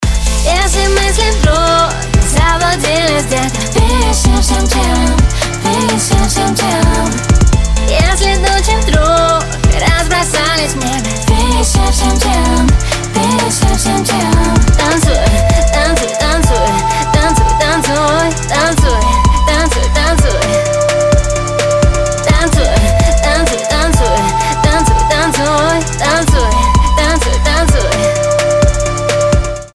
• Качество: 256, Stereo
поп
женский вокал
dance
RnB